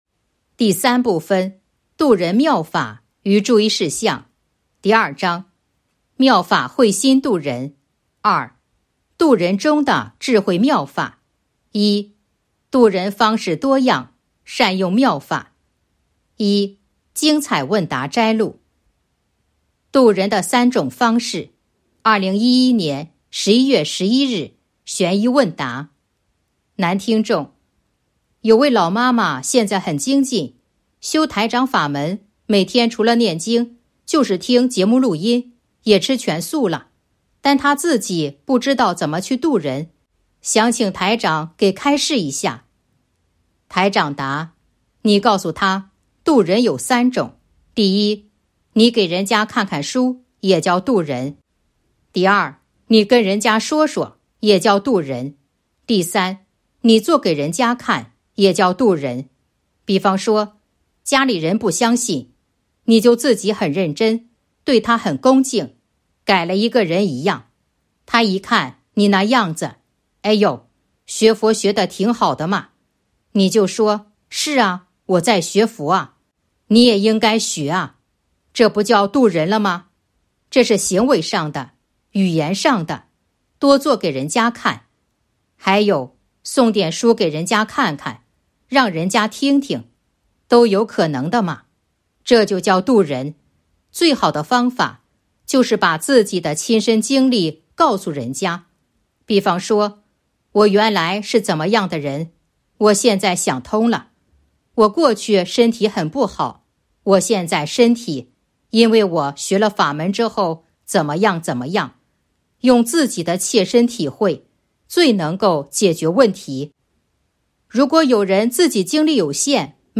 012.（一）度人方式多样，善用妙法 1. 精彩问答摘录《弘法度人手册》【有声书】 - 弘法度人手册 百花齐放